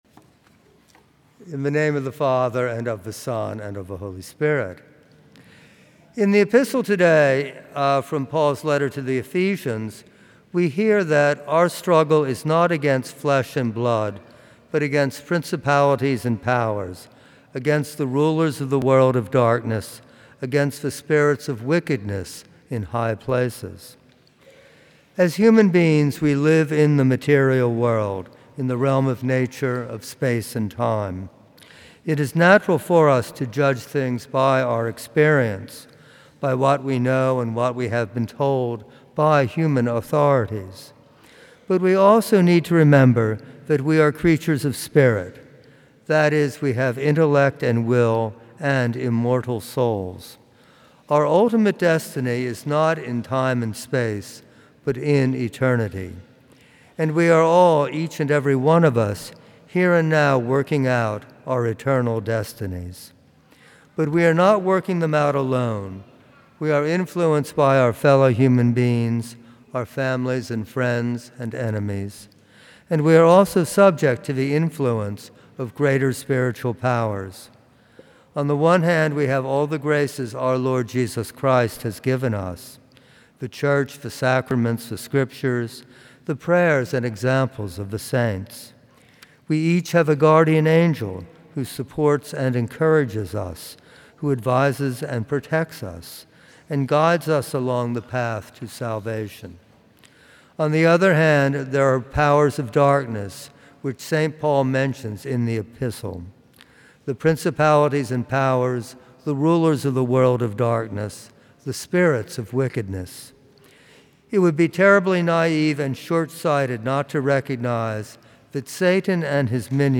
From Series: "Homilies"
Homilies that are not part of any particular series.